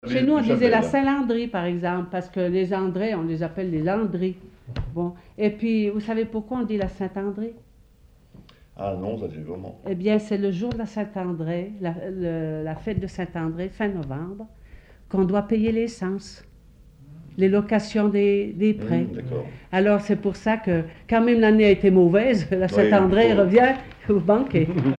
Catégorie Témoignage